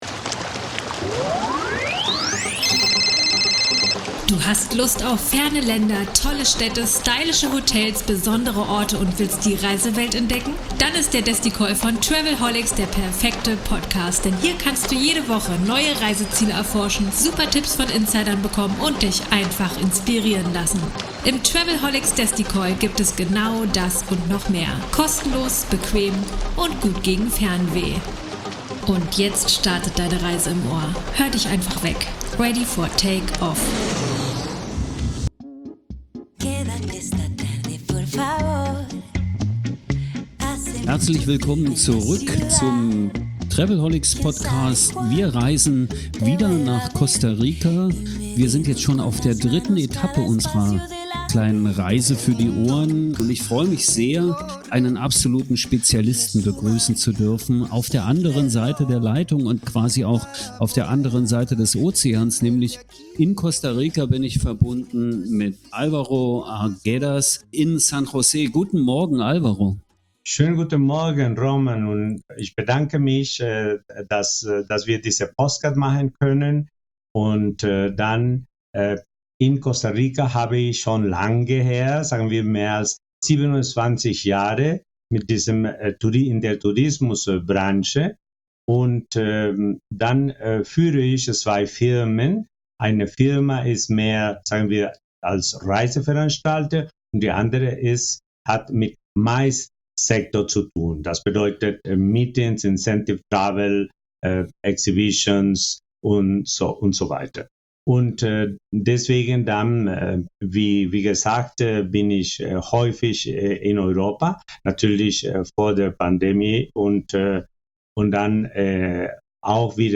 Ein "local", erfahrener Touristiker und sehr kompetenter Gesprächspartner.